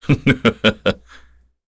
Chase-Vox_Happy1_kr.wav